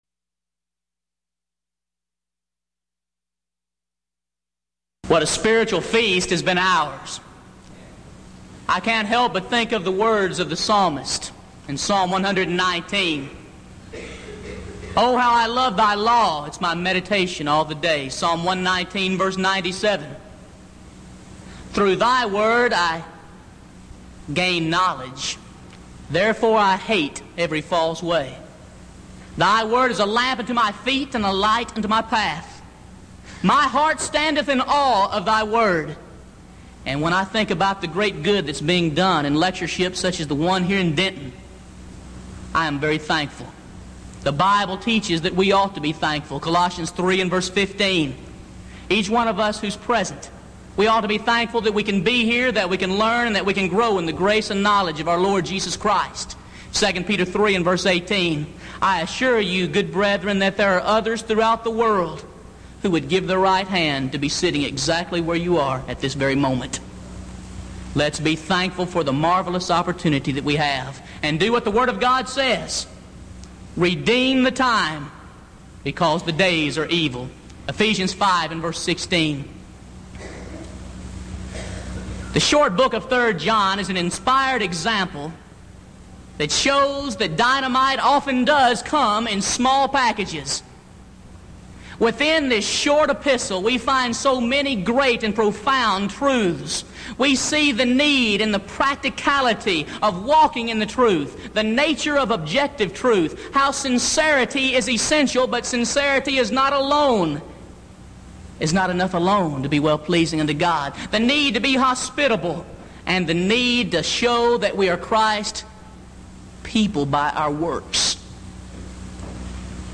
Series: Denton Lectures Event: 1987 Denton Lectures Theme/Title: Studies In I, II, III John